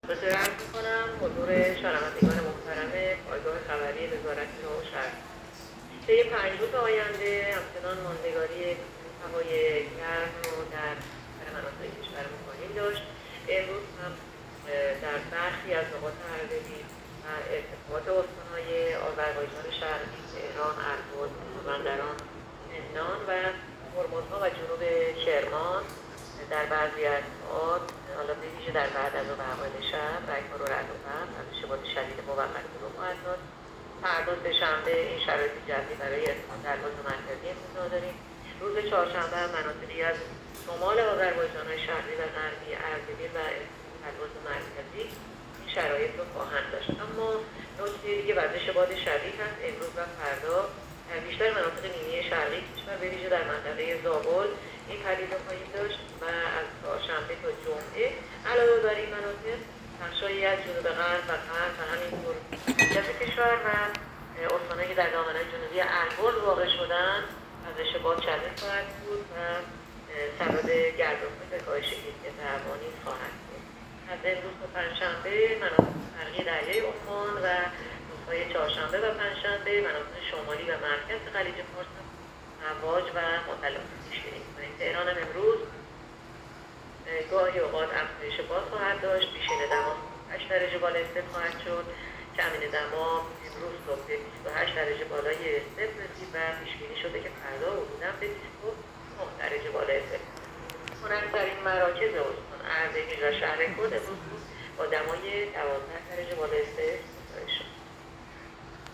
گزارش رادیو اینترنتی پایگاه‌ خبری از آخرین وضعیت آب‌وهوای ۶ مرداد؛